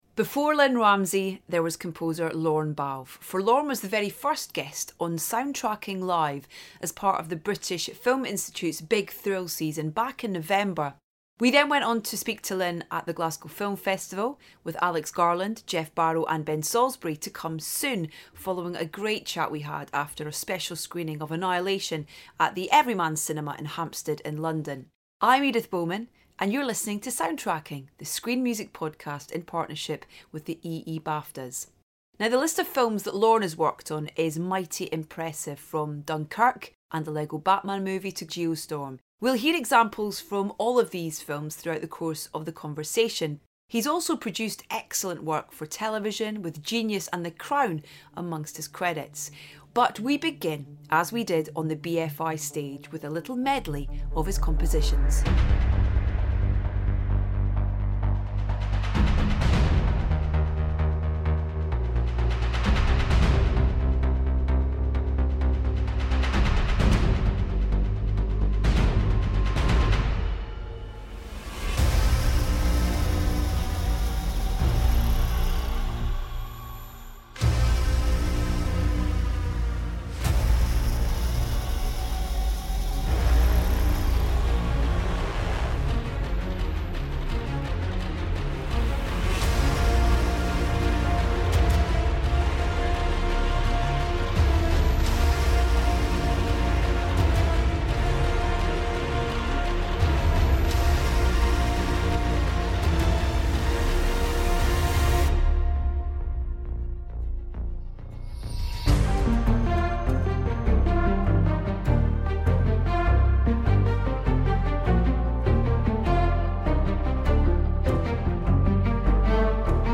Composer Lorne Balfe On Soundtracking Live! At The British Film Institute
For Lorne was the very first guest on Soundtracking Live, as part of the British Film Institute's Big Thrill Season back in November.